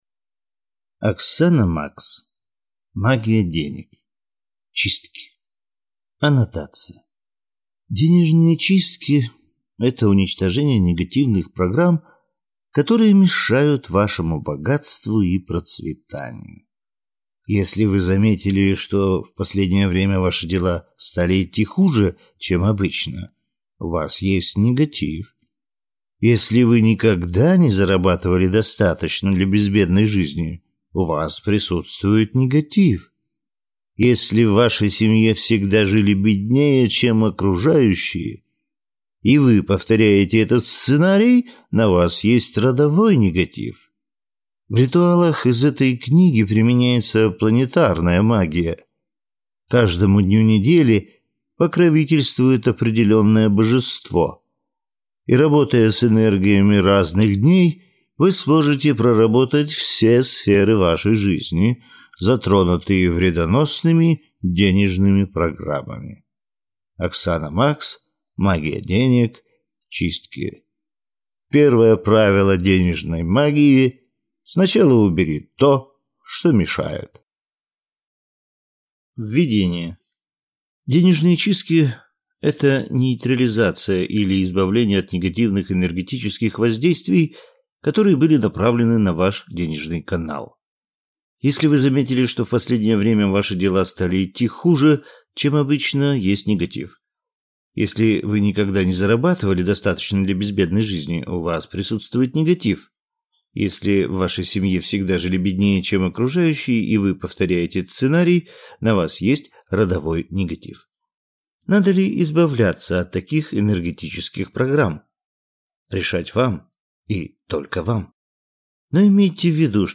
Аудиокнига Магия денег. Чистки | Библиотека аудиокниг